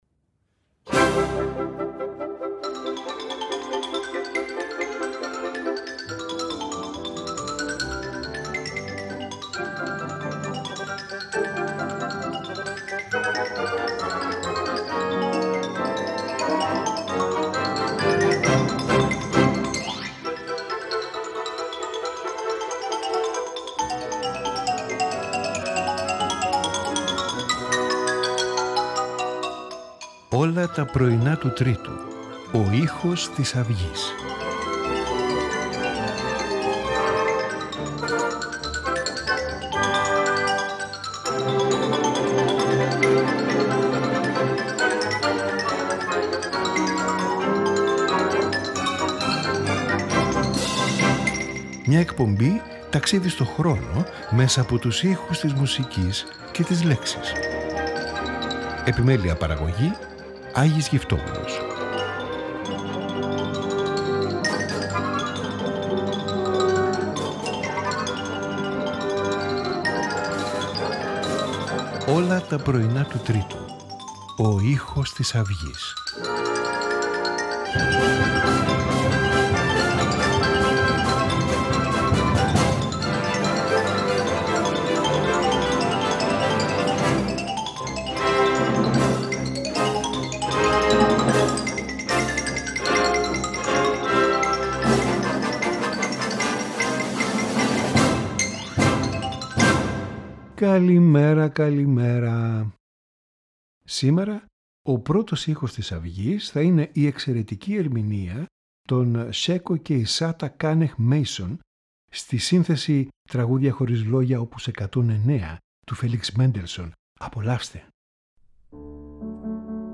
for Trumpet and Orchestra
Piano Concerto
String Quartet